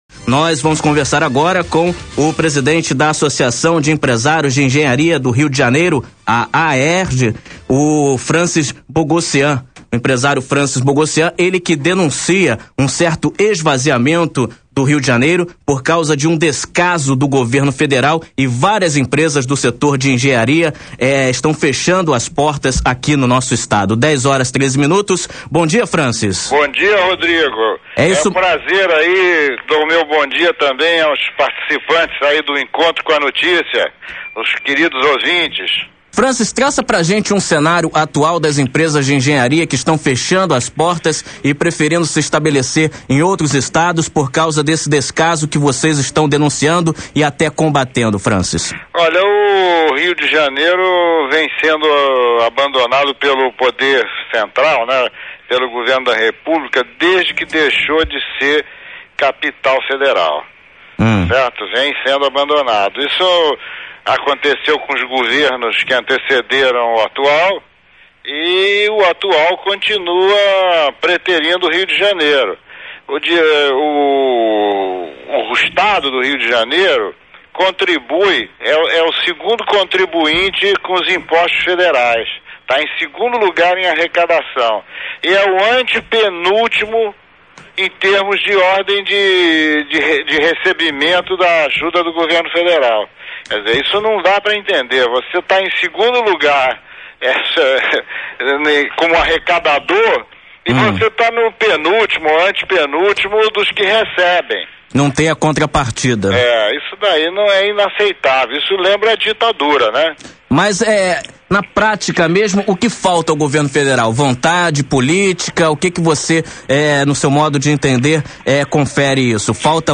imp-roquetefm-entrevista.wma